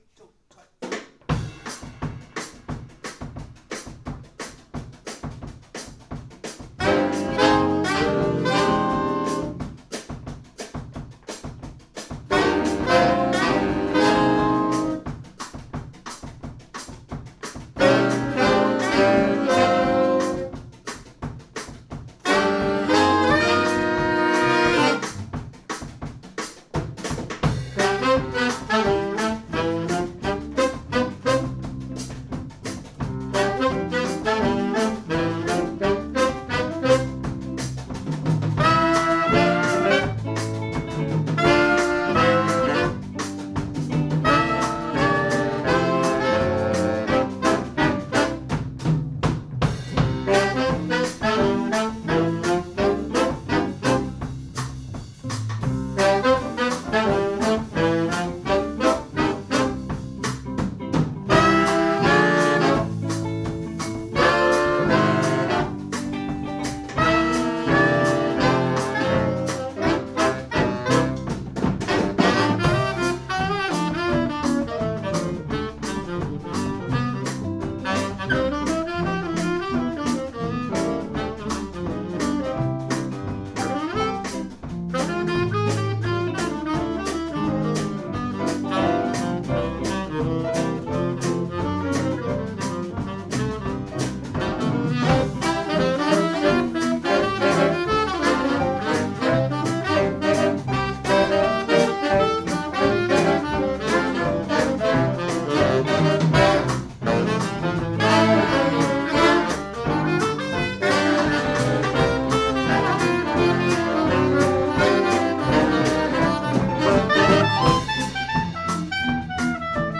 Jazz-nonetten på Lautrupgaard - optagelser 2025
Lyd i mp3-format, ca. 192 kbps, optaget stereo - ikke studieoptagelser!.